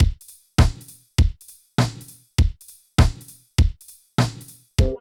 110 DRM LP-R.wav